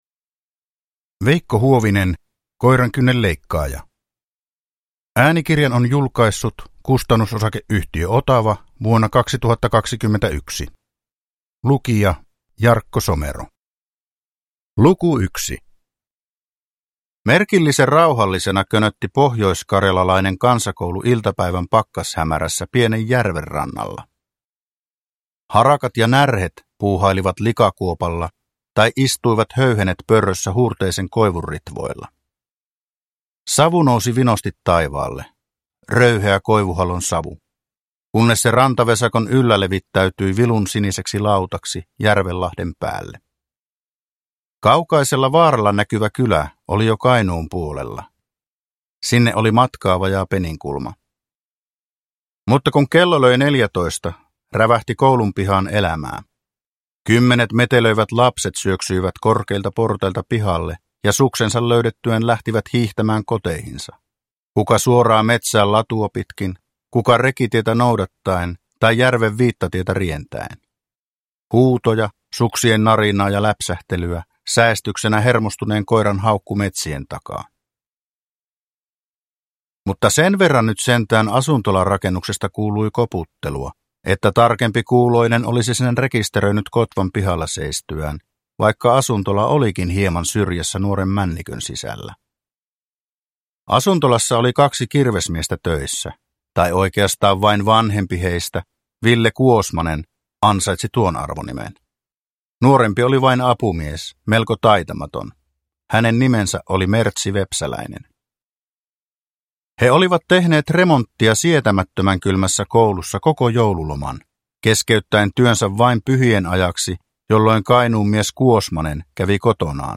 Koirankynnen leikkaaja – Ljudbok – Laddas ner